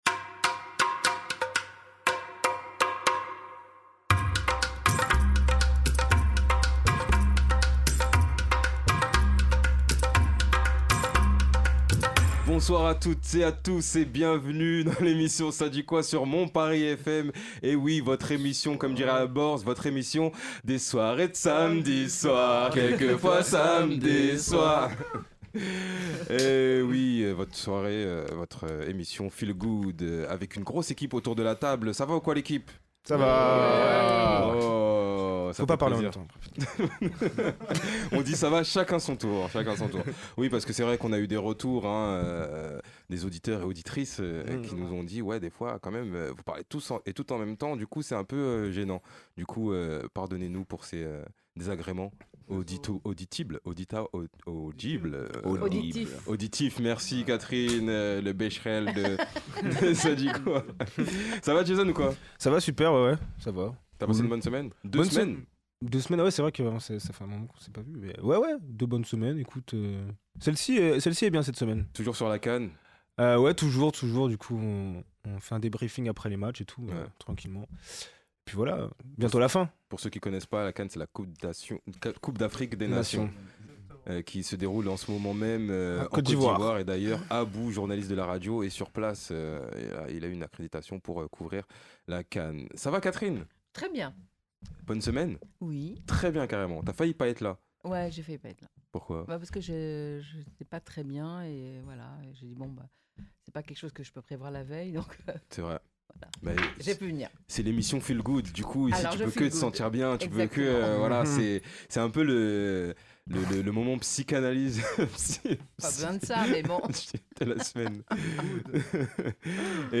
(Débat de la semaine)- Le Panel !?